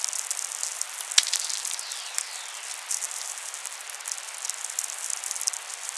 Radio Observatory